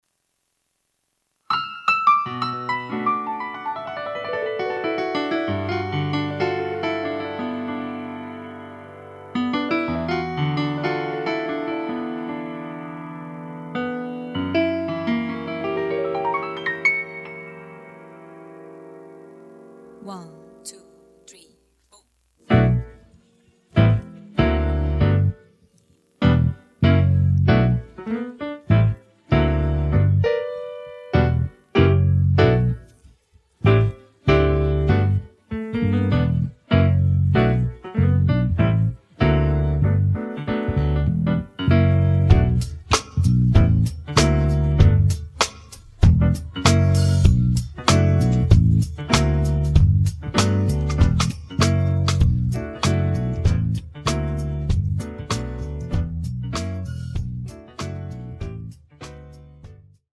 음정 원키 3:24
장르 가요 구분 Voice Cut